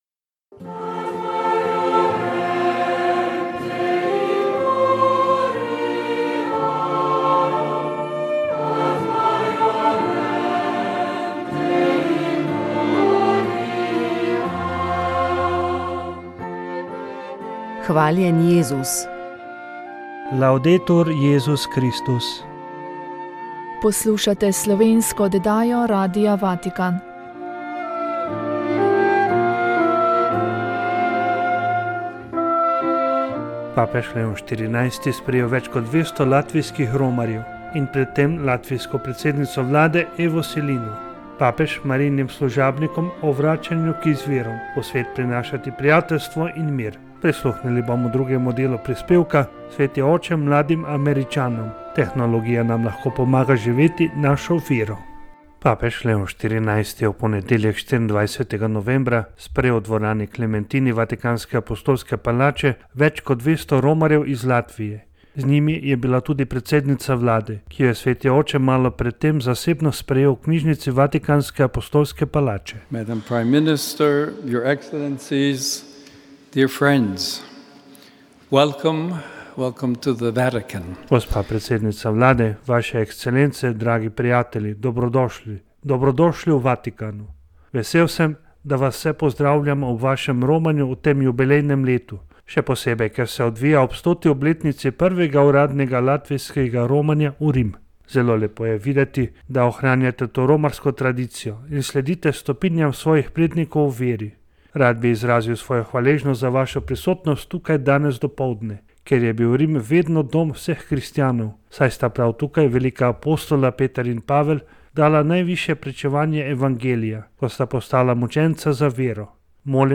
V tokratni oddaji smo spregovorili o maturi, v našem studiu je bil gost direktor državnega izpitnega centra dr. Darko Zupanc. O izboru maturitetnih predmetov, o ocenjevanju in kriterijih, vpogledih in veljavi mature pri nadaljevanju študija je tekla beseda.